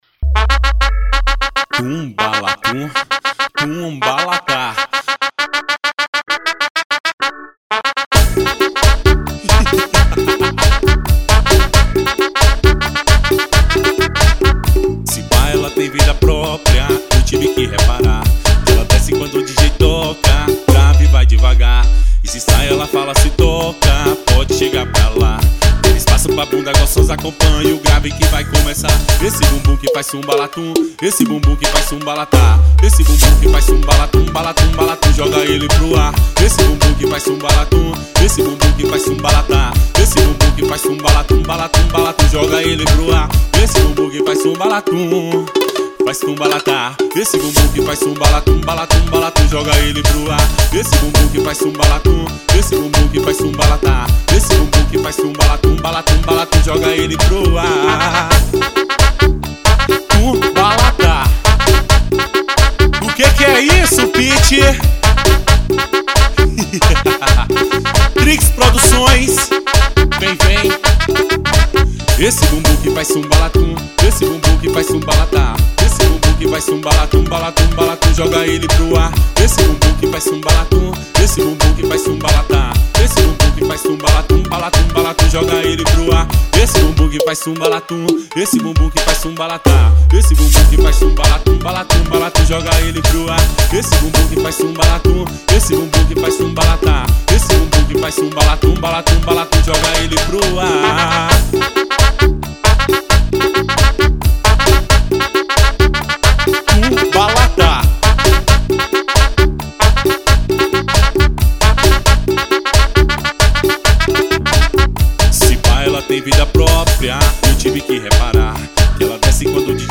FUNK.